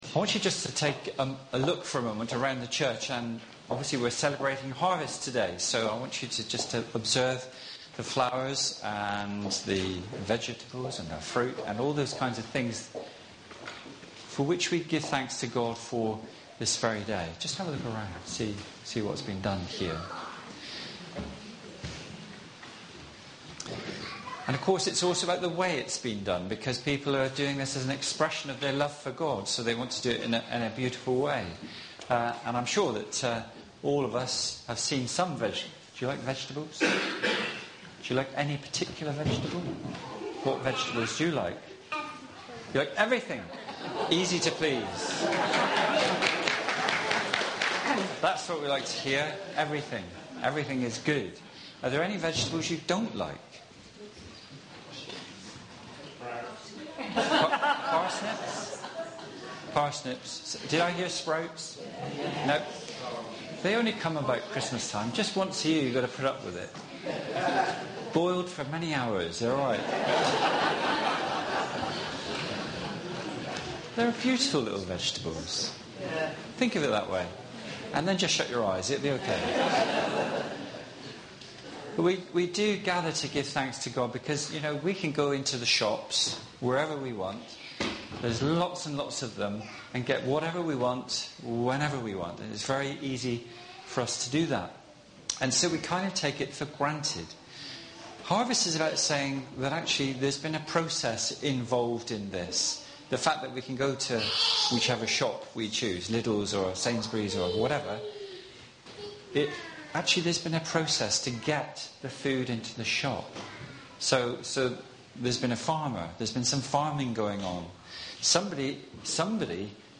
Sermon-2-Oct-2016.mp3